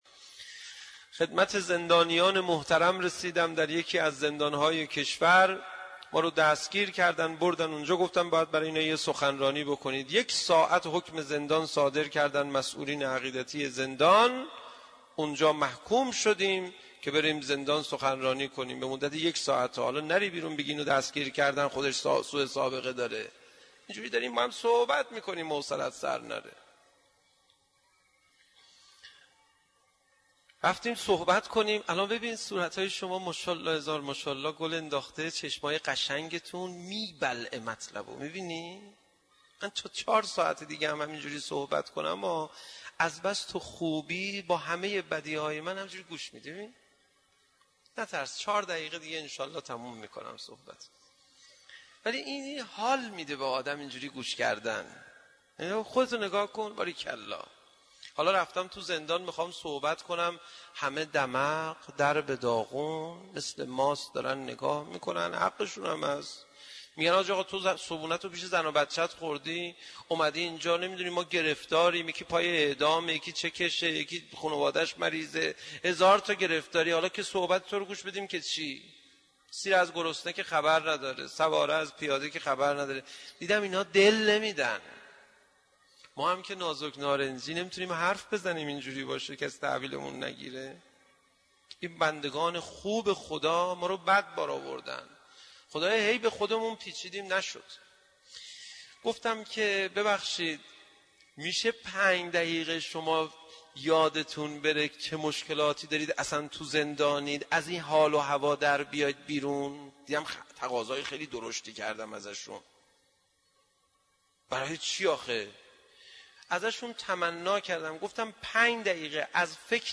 اذان-خصوصی.mp3